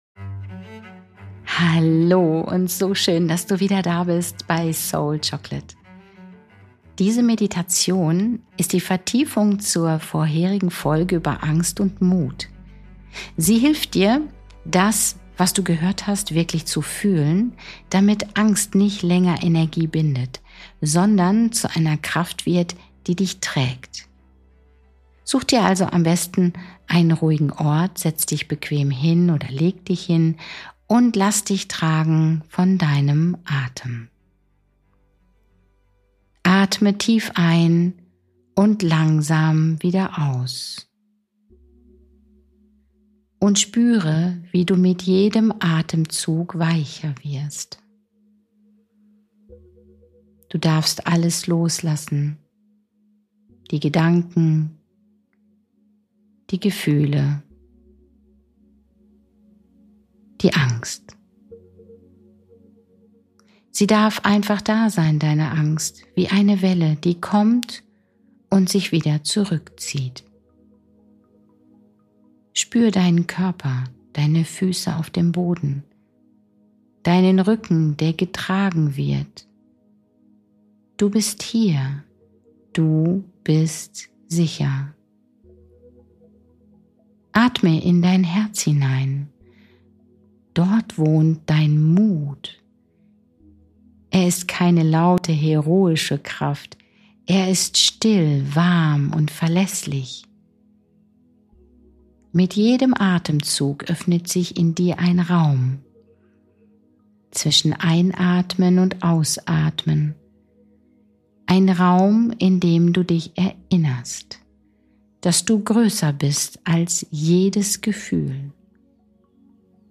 Nr. 74 - Geführte Meditation: Mut atmen – durch die Angst hindurch